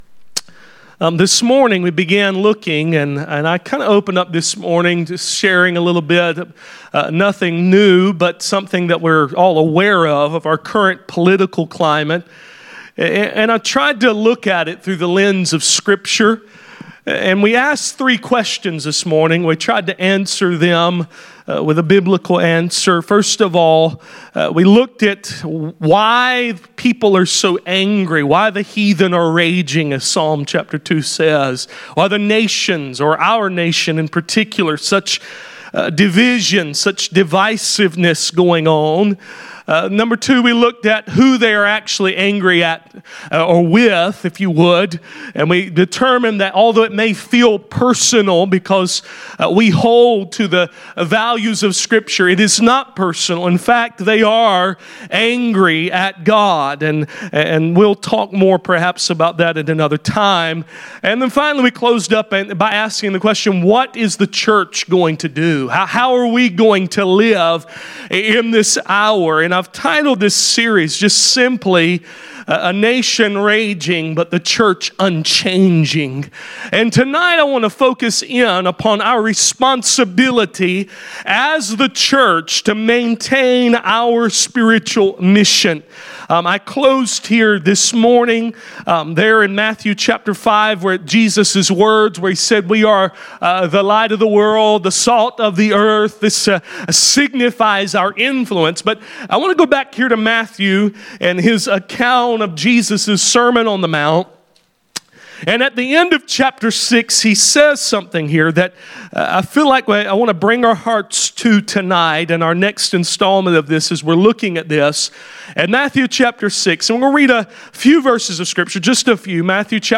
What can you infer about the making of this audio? Passage: Matthew 6:31-33 Service Type: Sunday Evening « A nation raging but a church unchanging The time called the “Tribulation” »